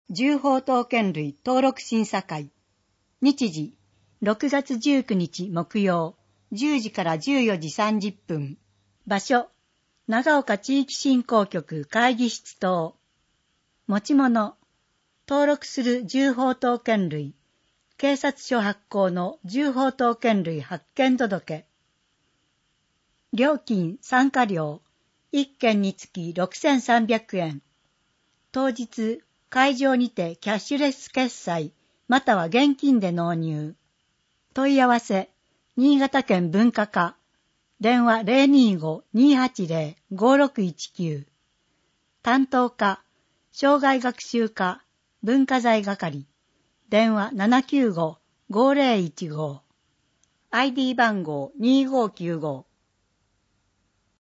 文字を読むことが困難な視覚障害者や高齢者や、市報を聞きたい方のために、「魚沼音声訳の会」のご協力により市報うおぬま音声版（ＭＰ3）をお届けします。